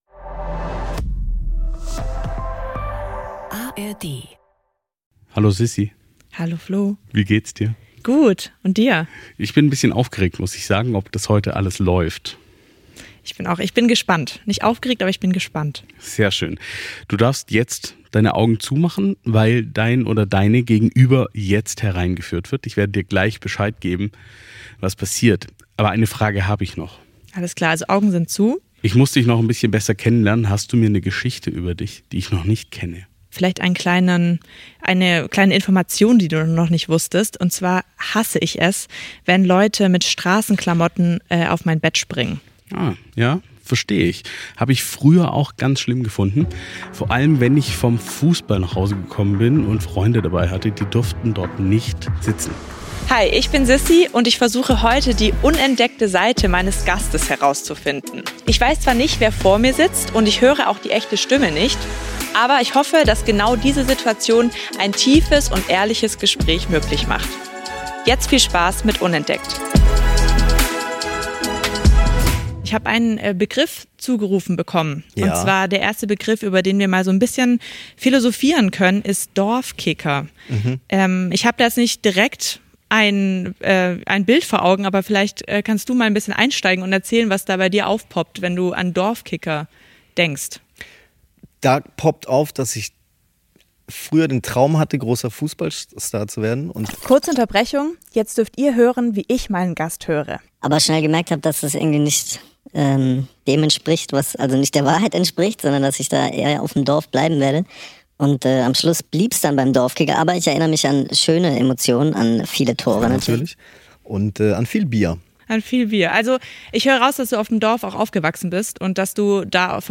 "UNENTDECKT" ist ein Podcast-Format, das die Begegnung zwischen einem Host und einem geheimen Gast auf einzigartige Weise inszeniert.
Der Clou: Eine KI verfremdet die Stimme des Gastes – digitale Spiegel zeigen nur das Nötigste.